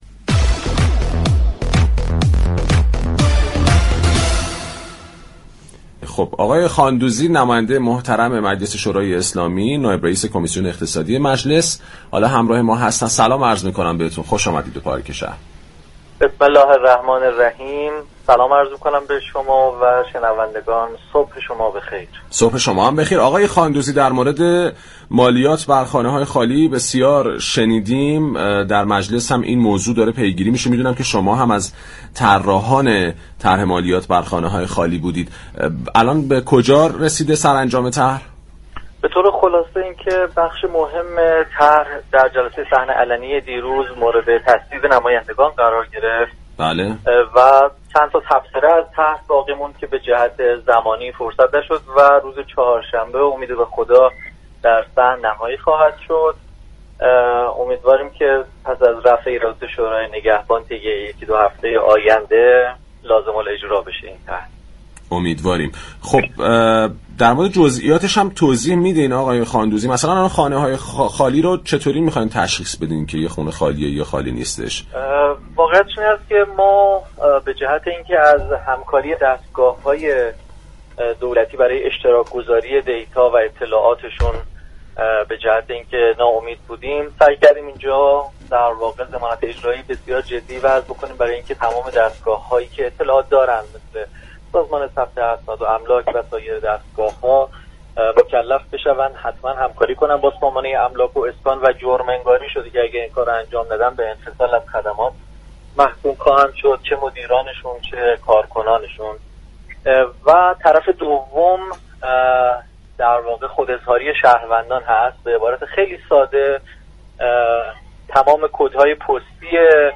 سید احسان خاندوزی، نماینده مجلس شورای اسلامی و نایب رئیس كمیسیون اقتصادی مجلس درمورد طرح مالیات بر خانه های خالی در گفتگو با پارك شهر اظهار داشت: نهادهای متخلف دو برابر شهروندان عادی باید برای خانه های خالی مالیات پرداخت كنند.